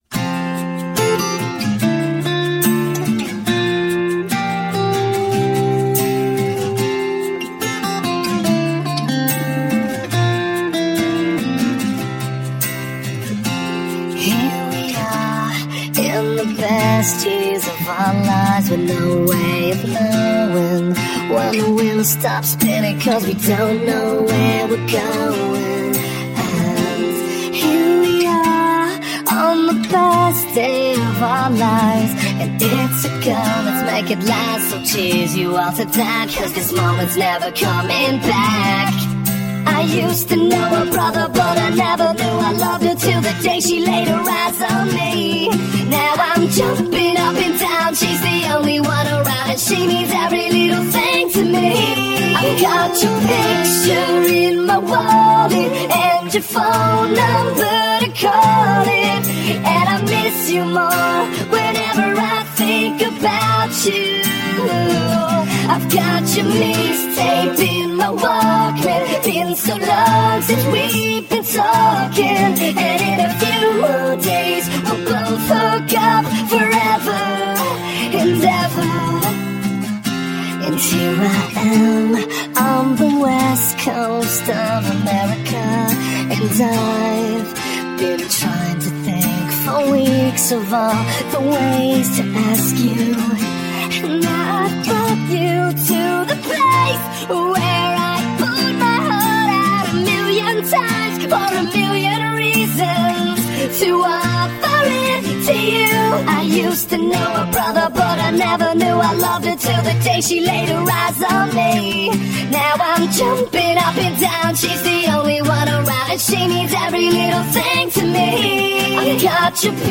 Christian pop punk duo
*Converted for key of C instrument